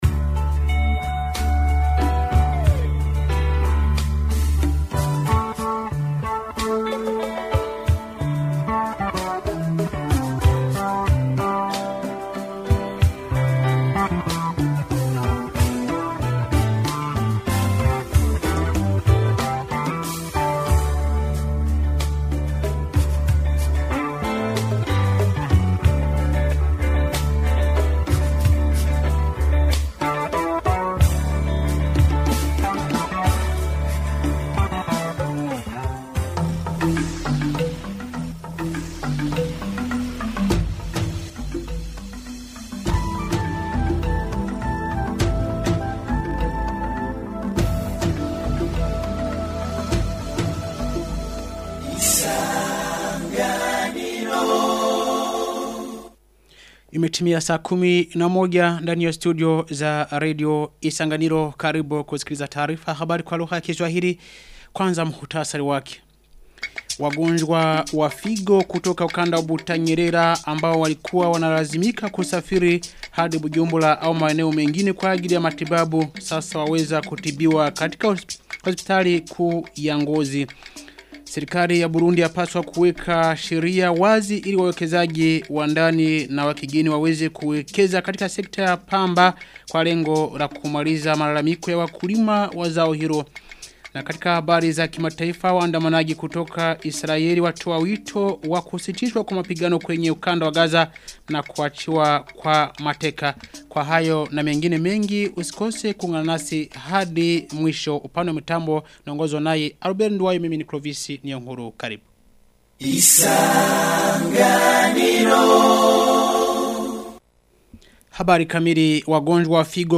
Taarifa ya habari ya tarehe 27 Agosti 2025